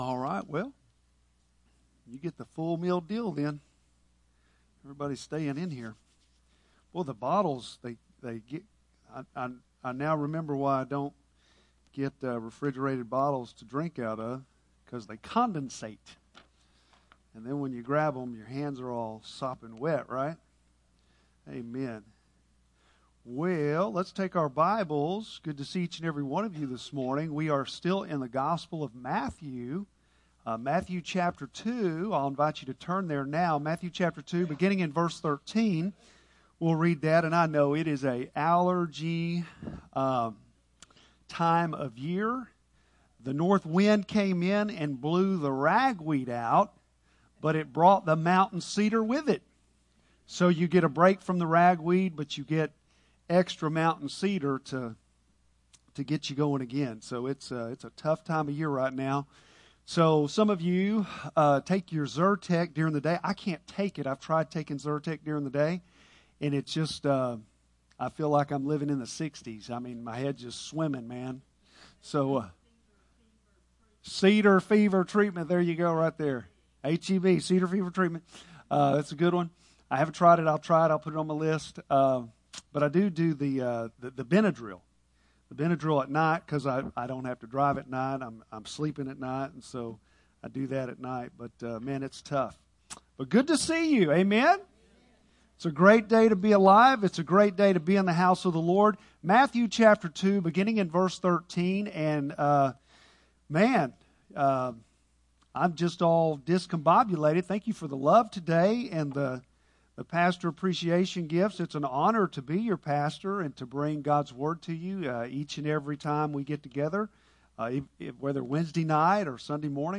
Bible Text: Matthew 2:13-23 | Preacher